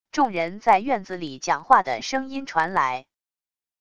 众人在院子里讲话的声音传来wav音频